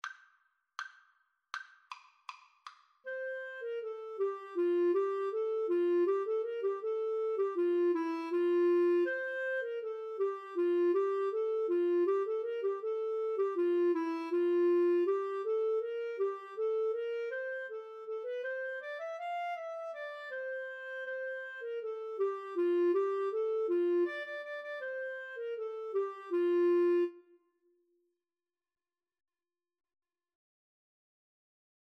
Very Fast =80
Clarinet Duet  (View more Easy Clarinet Duet Music)